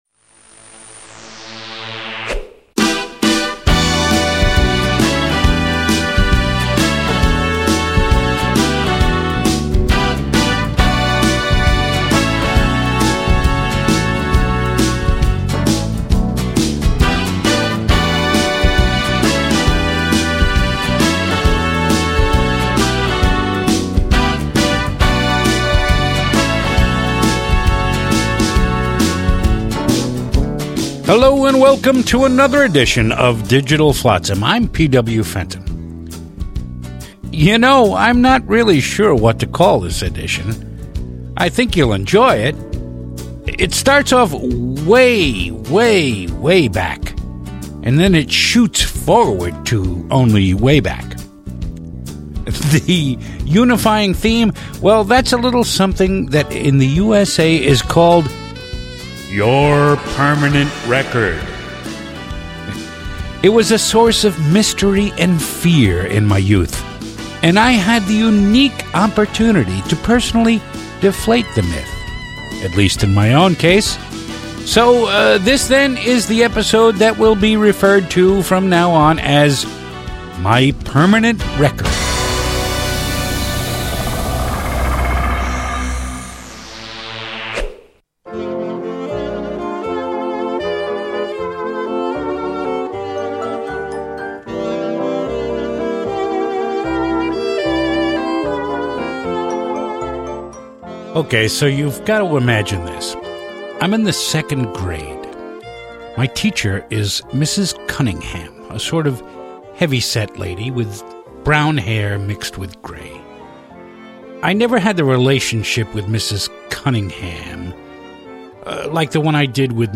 It appeared for several years nationally on Sirius Satellite Radio. We are proud to offer these great spoken word pieces again.